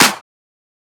edm-clap-02.wav